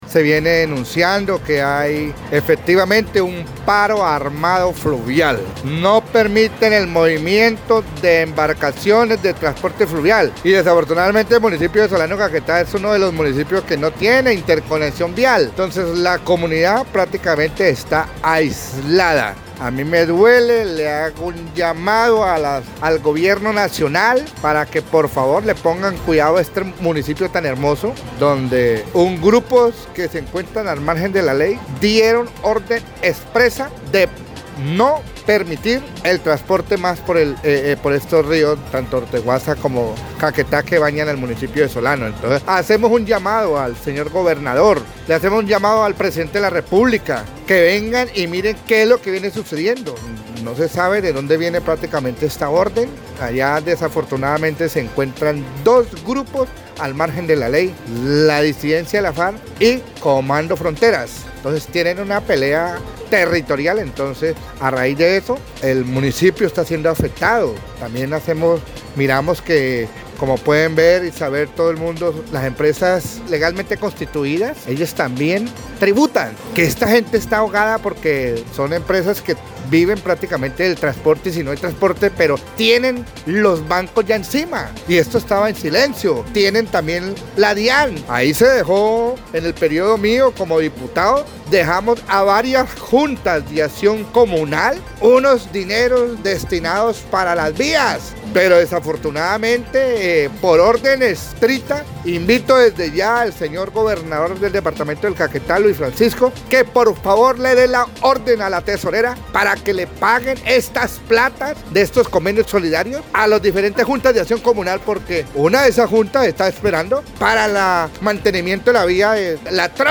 Luis Alfredo Silva Neira, ex diputado y líder de esta región, explicó que esta situación pone en serios problemas económicos a transportadores fluviales y a la población en general, por la imposibilidad de transportarse por dichas fuentes hídricas.